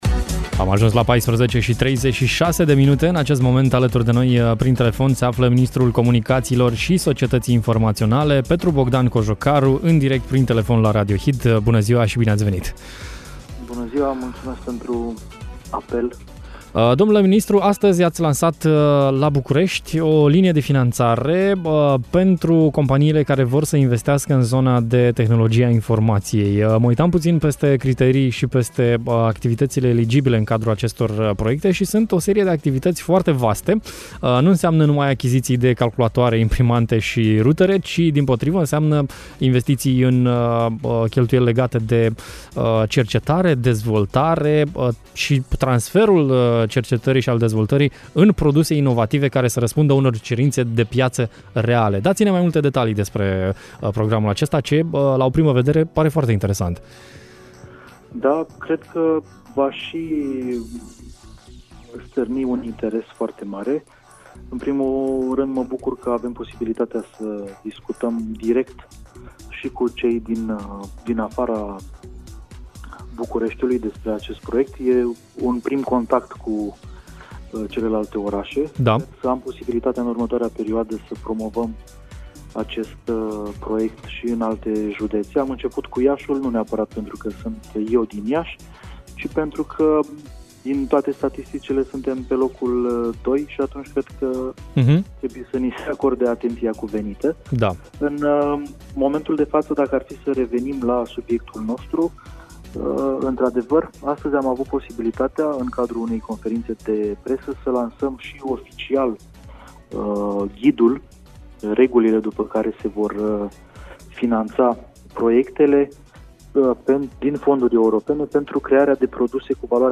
Pe 17 octombrie a fost lansat un program de finanțare care spijină companiile din zona de IT să lanseze pe piața produse și servicii inovative. Mai multe detalii am aflat de la ministrul Comunicațiilor și Societății Informaționale, Petru Bogdan Cojocaru: